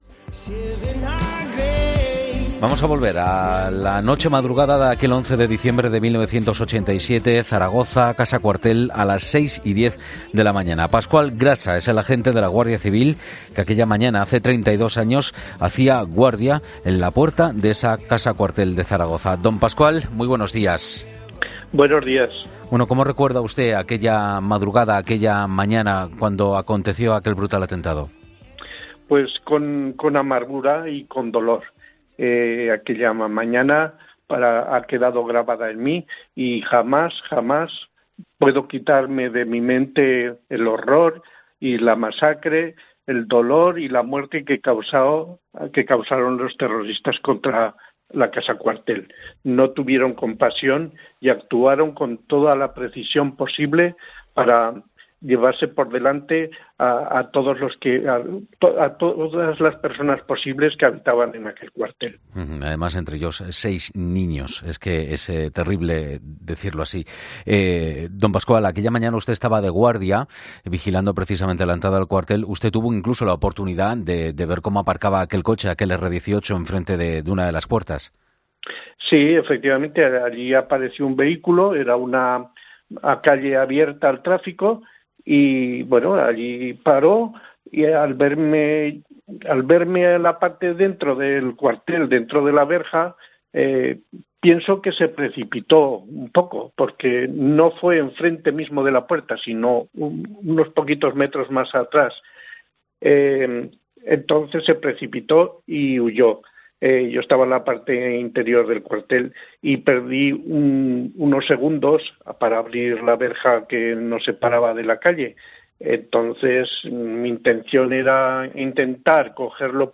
Con un nudo en la garganta recuerda como perdió unos segundos para abrir la verja.